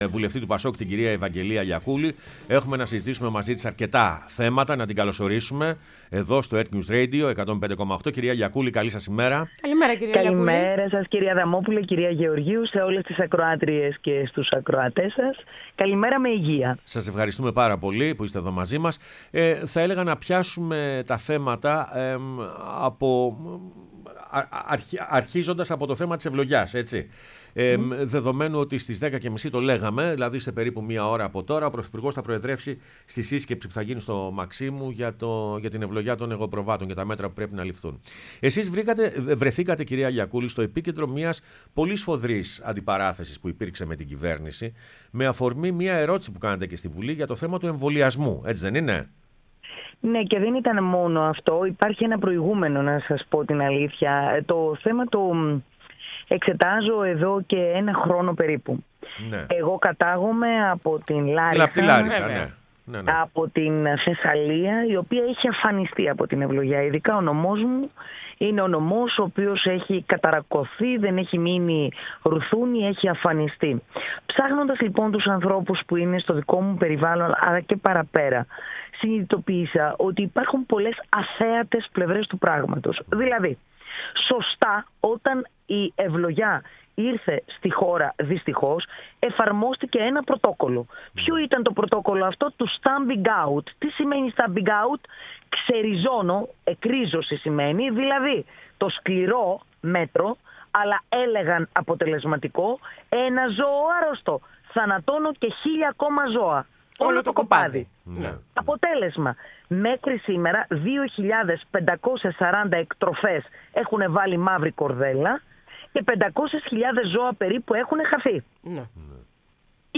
Η Ευαγγελία Λιακούλη, βουλευτής ΠΑΣΟΚ, μίλησε στην εκπομπή «Πρωινές Διαδρομές»